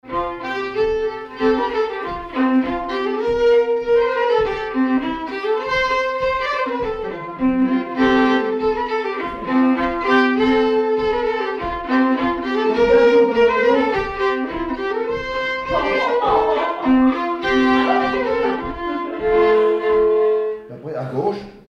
Villard-sur-Doron
circonstance : bal, dancerie
Pièce musicale inédite